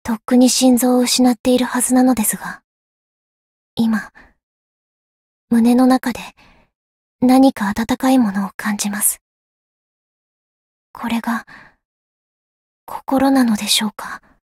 灵魂潮汐-德莱洁恩-情人节（摸头语音）.ogg